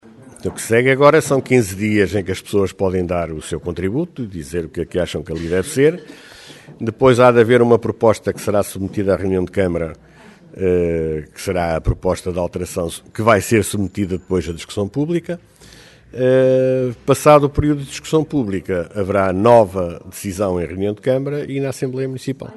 Aberto que foi o procedimento, o vereador do urbanismo explica que de seguida haverá uma consulta pública durante 15 dias durante a qual as pessoas se podem pronunciar sobre como querem que aquela área seja ocupada.